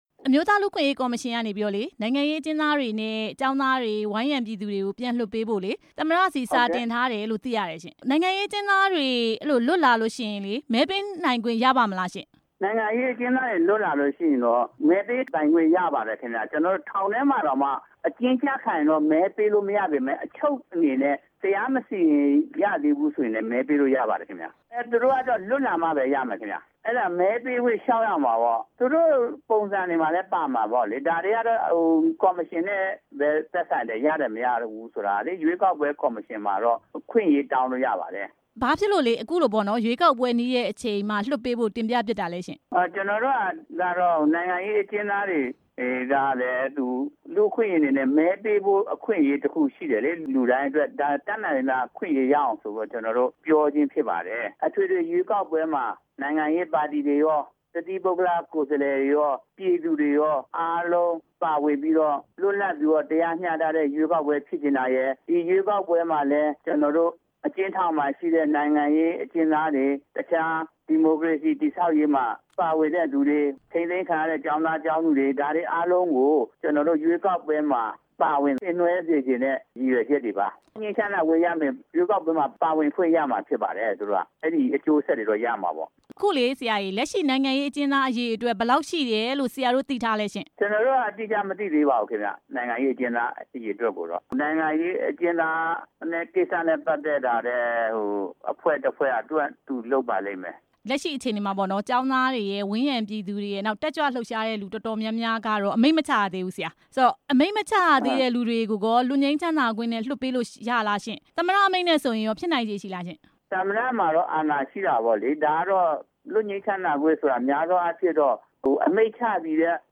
အမျိုးသားလူ့အခွင့်အရေး ကော်မ ရှင်အဖွဲ့ဝင် ဒေါက်တာဉာဏ်ဇော်နဲ့ မေးမြန်းချက်